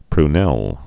(pr-nĕl)